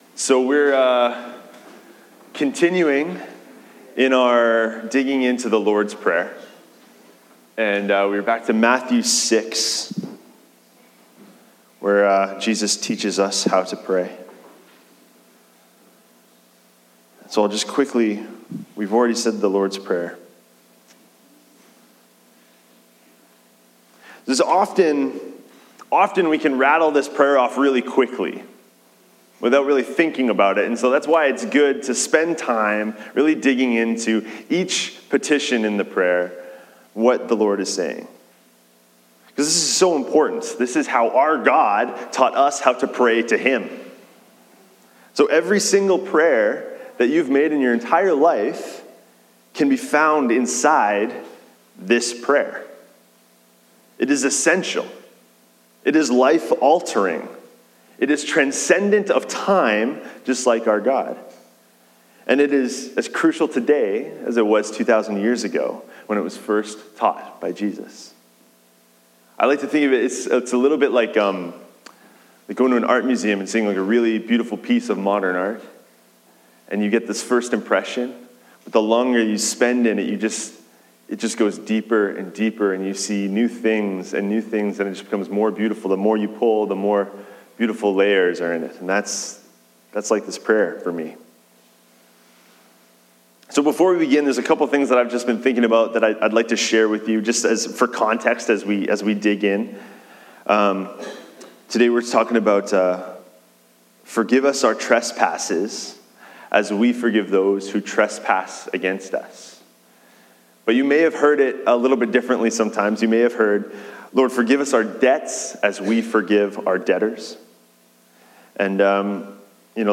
Sermons | Church of Our Lord